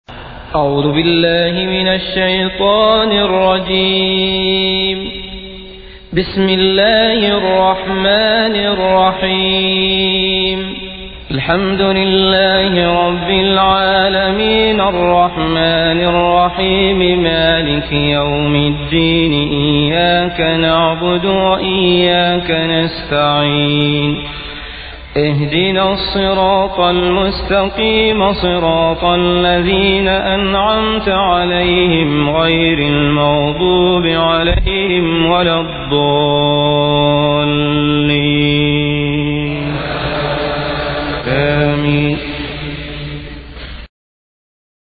Listen to Quran Audio
Surah-Al-Fatihah-Abdullah-al-Mathrud.mp3